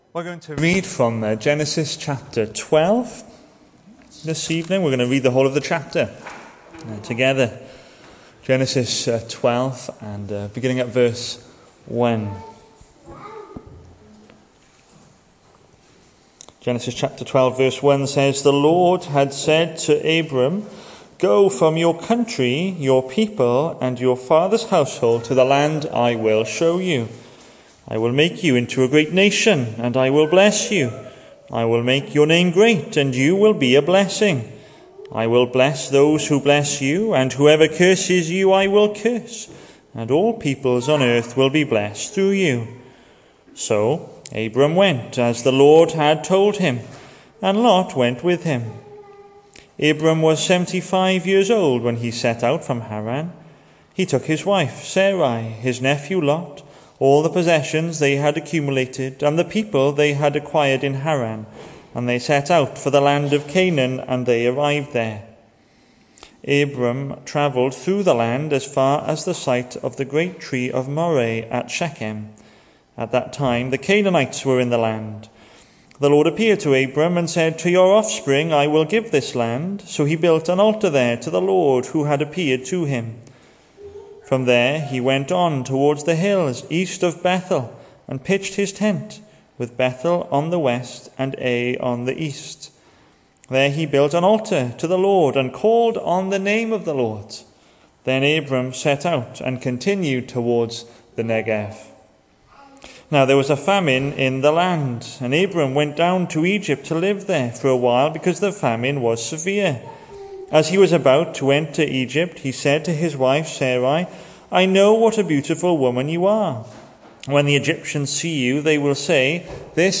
Hello and welcome to Bethel Evangelical Church in Gorseinon and thank you for checking out this weeks sermon recordings.
The 7th of September saw us hold our evening service from the building, with a livestream available via Facebook.